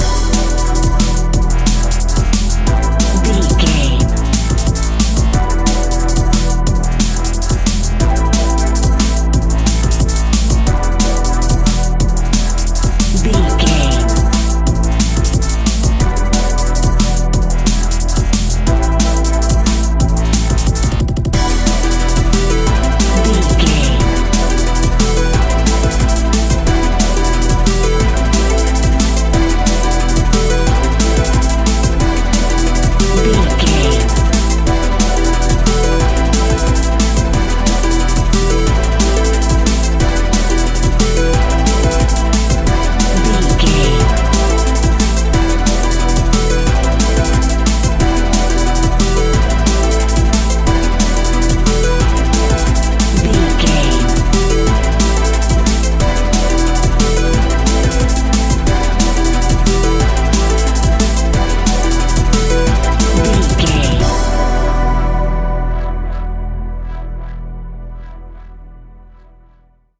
Epic / Action
Fast paced
Aeolian/Minor
Fast
aggressive
dark
driving
energetic
intense
futuristic
powerful
synthesiser
drums
drum machine
break beat
electronic
sub bass
synth leads
synth bass